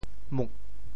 Details of the phonetic ‘mug4’ in region TeoThew
IPA [mut]